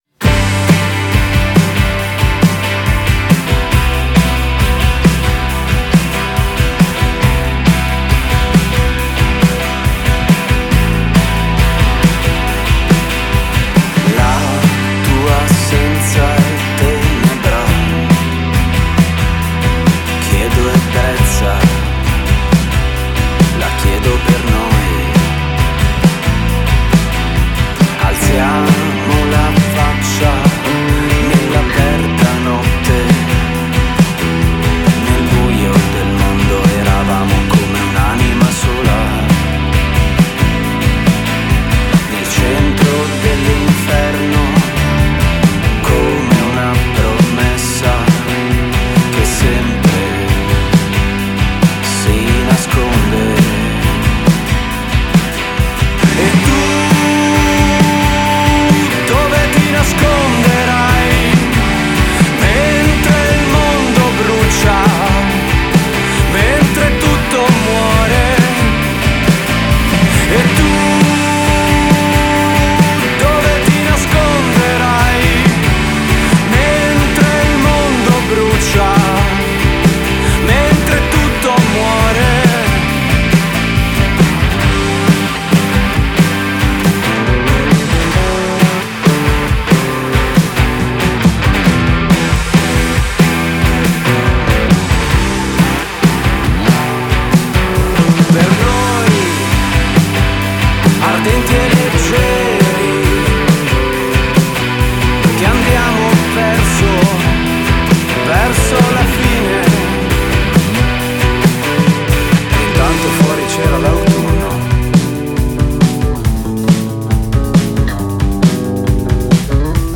gruppo post-punk